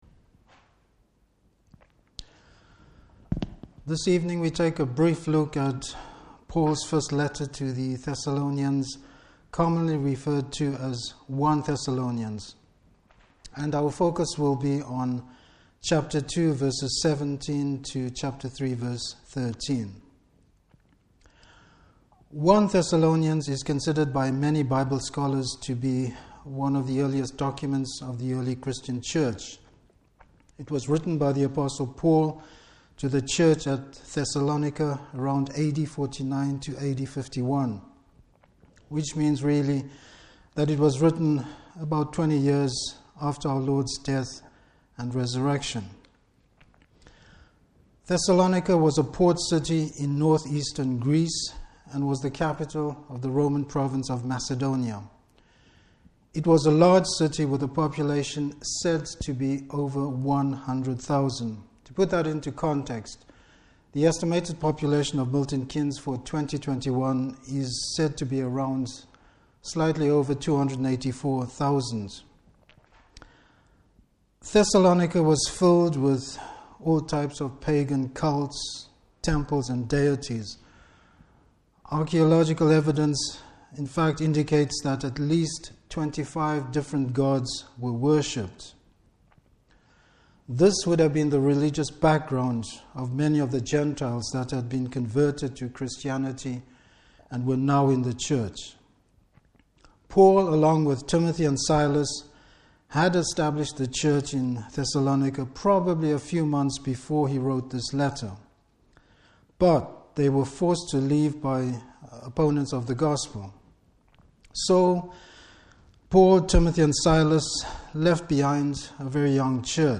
1 Thessalonians 2:17-3.13. Service Type: Evening Service Paul’s fellowship with the Thessalonian Church.